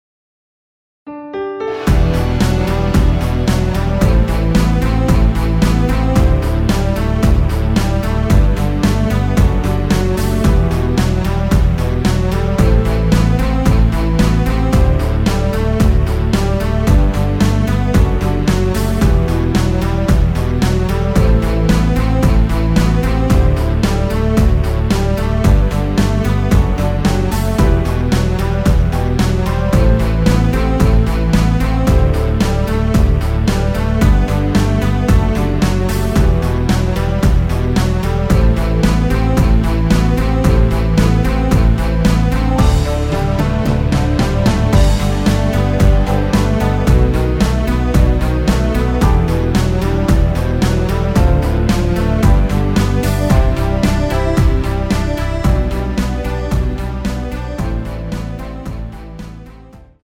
(-6)내린멜로디 포함된 MR 입니다.(미리듣기 참조)
◈ 곡명 옆 (-1)은 반음 내림, (+1)은 반음 올림 입니다.
멜로디 MR이라고 합니다.
앞부분30초, 뒷부분30초씩 편집해서 올려 드리고 있습니다.